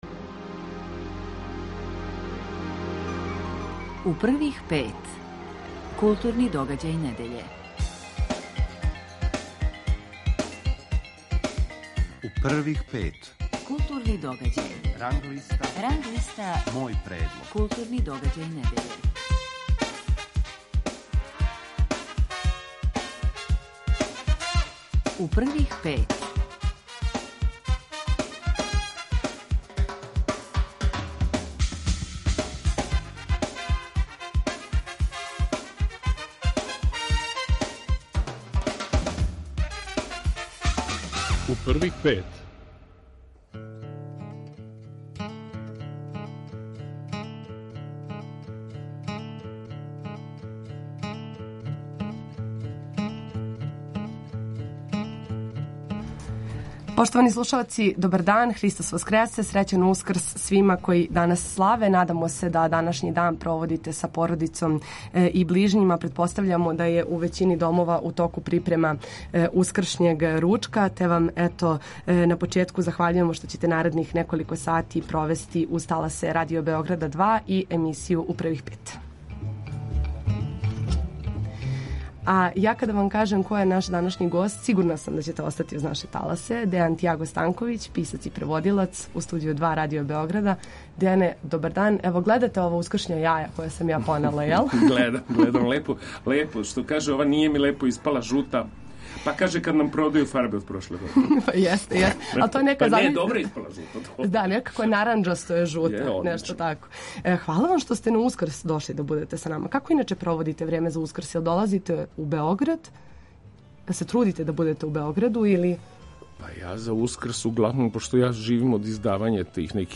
Гост емисије је Дејан Тиаго Станковић.